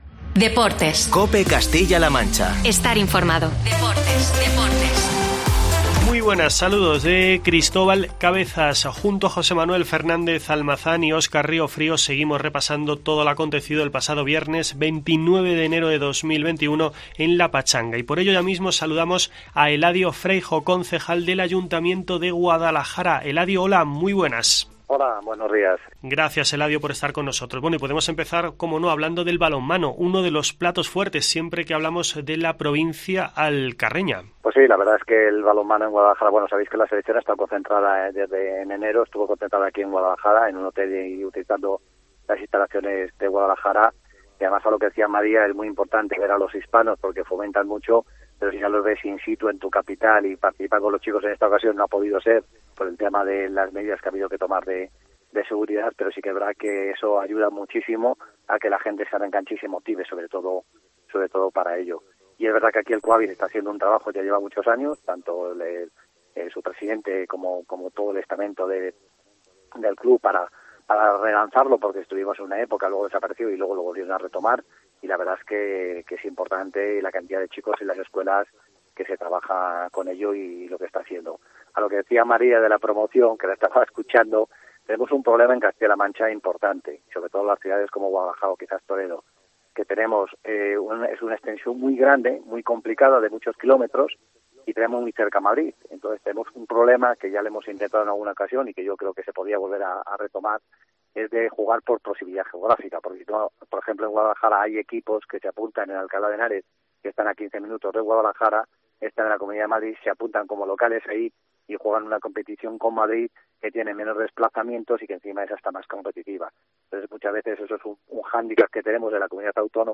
AUDIO: Escucha en “La Pachanga” la entrevista con el concejal del Ayuntamiento de Guadalajara